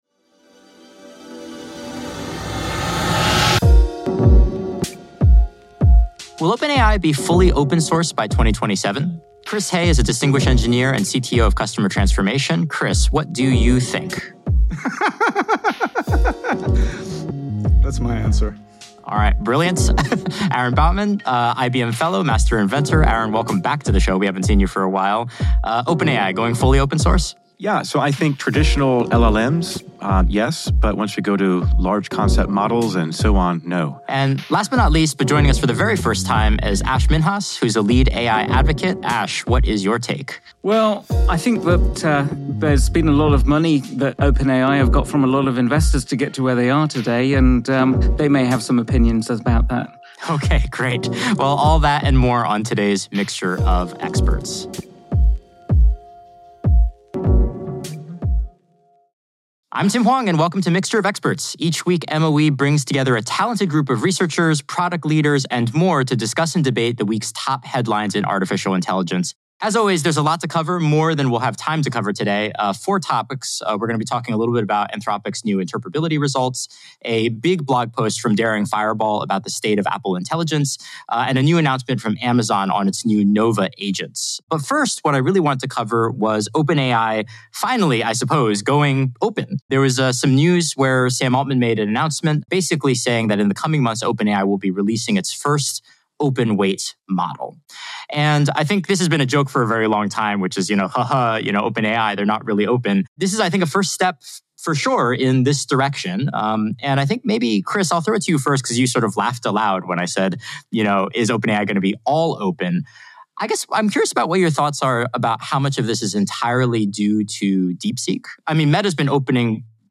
Today, the experts chat IBM’s 2024 Cost of a Data Breach Report and analyze how gen AI could reduce the cost of cyber threats.